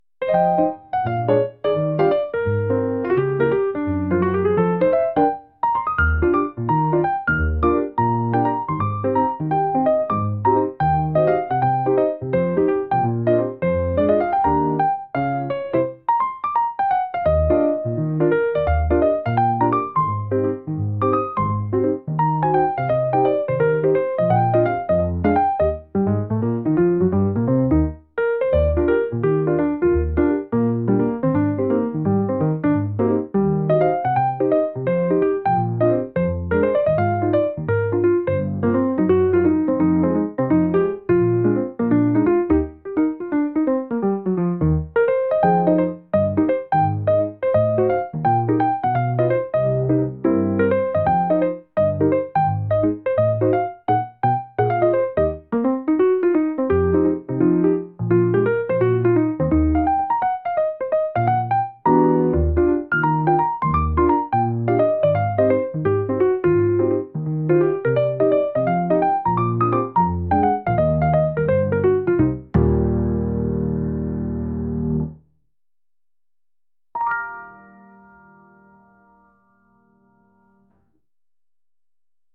週末を過ごすジャズのピアノ曲です。